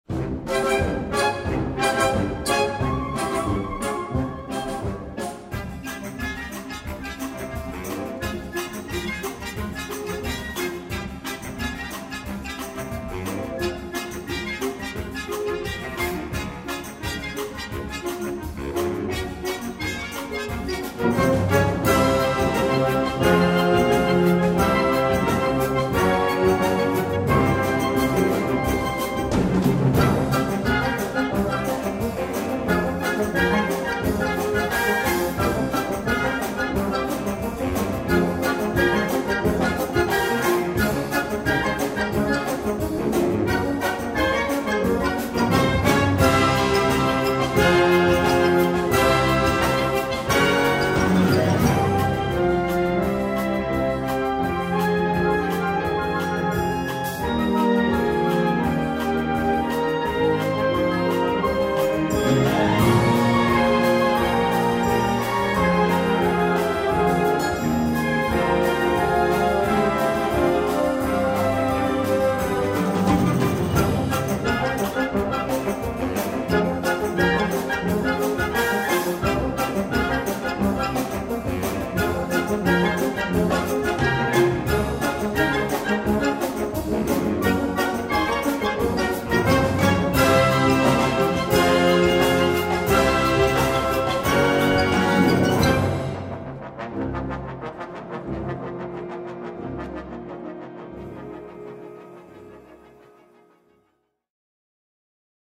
Gattung: Suite Symphonique
Besetzung: Blasorchester